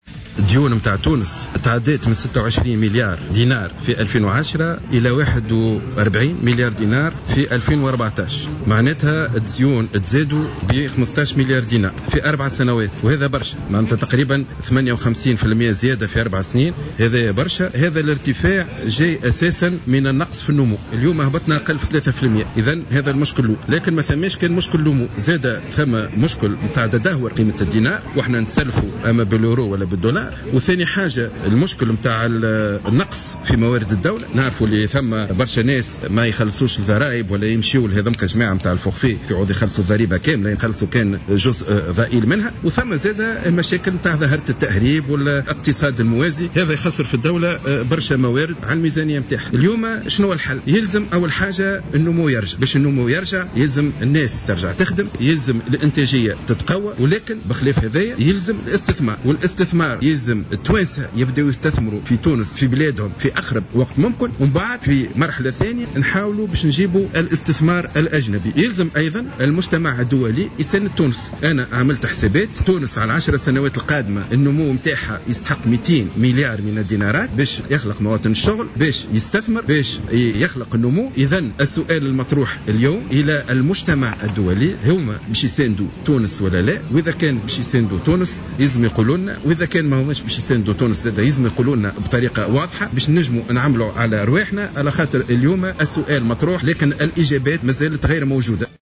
أعلن وزير المالية سليم شاكر اليوم الأربعاء خلال انعقاد أشغال المنتدى الاقتصادي المغاربي بالعاصمة أن ديون تونس ارتفعت بنسبة 58 % خلال 4 سنوات.